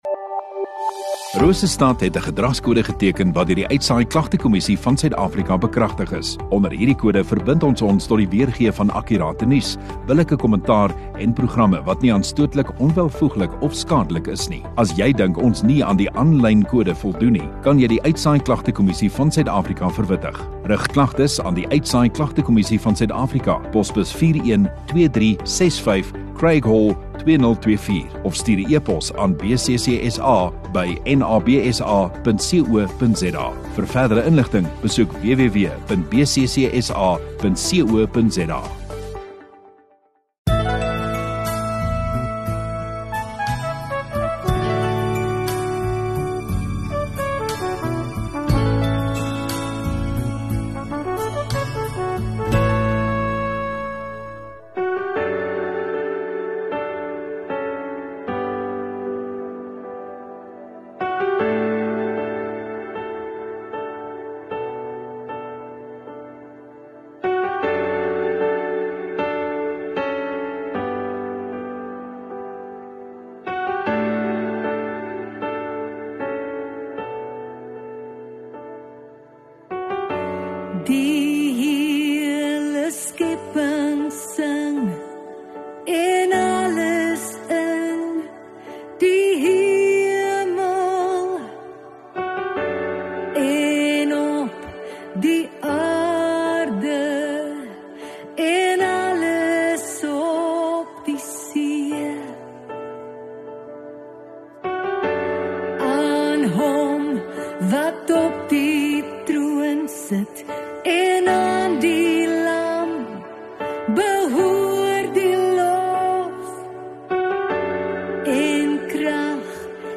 2 Mar Sondagaand Erediens